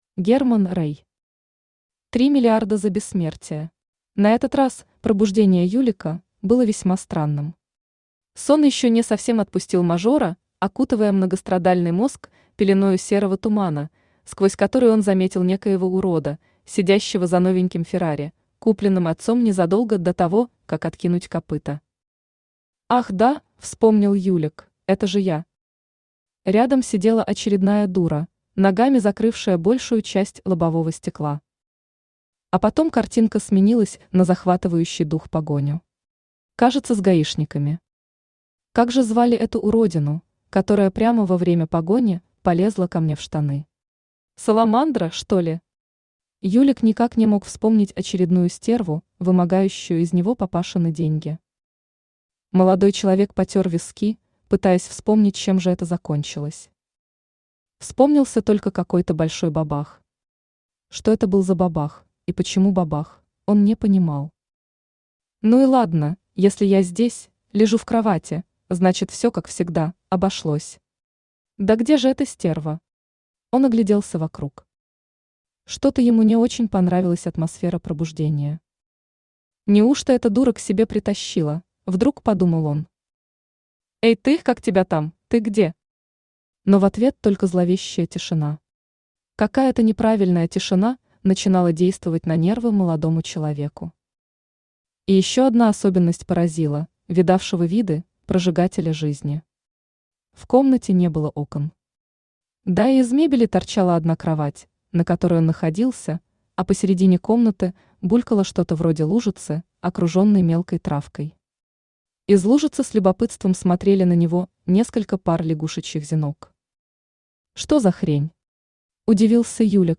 Aудиокнига Три миллиарда за бессмертие Автор Герман Рэй Читает аудиокнигу Авточтец ЛитРес.